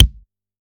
taiko-soft-hitnormal.mp3